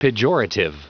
Prononciation du mot pejorative en anglais (fichier audio)
Prononciation du mot : pejorative